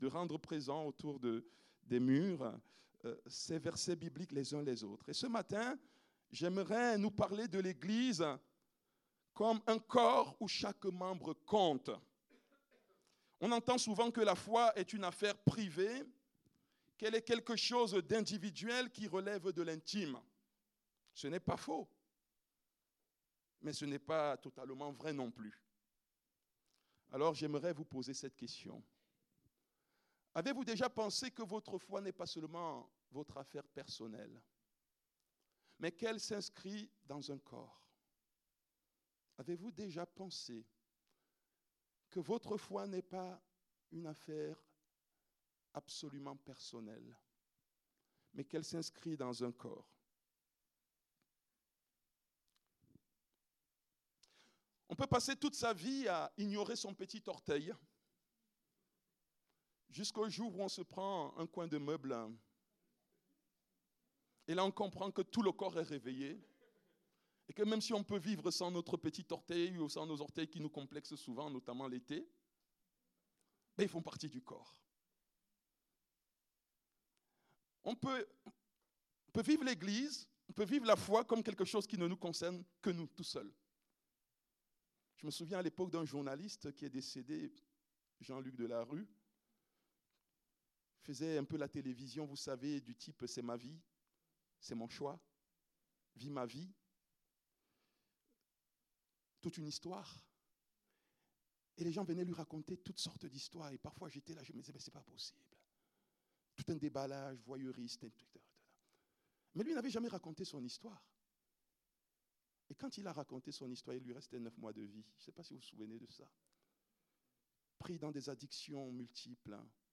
Culte du dimanche 28 septembre 2025, prédication